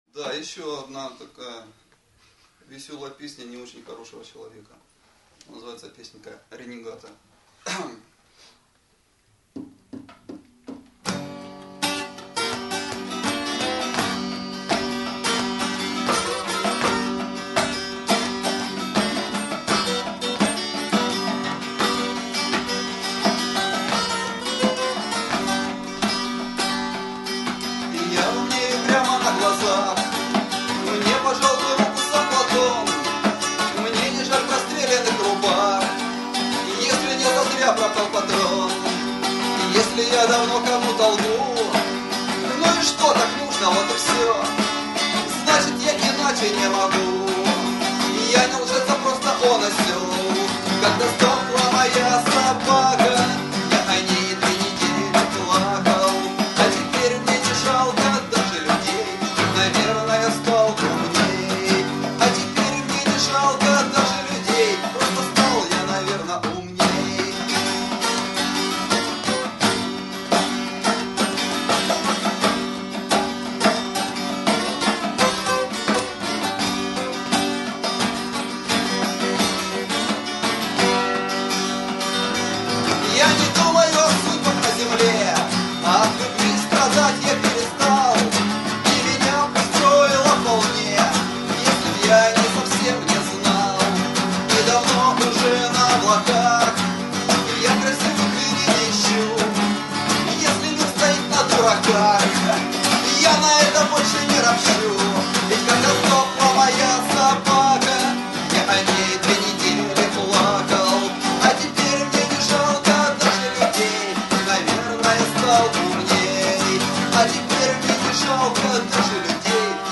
Концерт Краматорской Музыкально - Поэтической Студии им. Петра Красенца (КМПС),
состоявшийся 04.06.2009 в Харьковском Клубе Любителей Русского Рока (КлрР)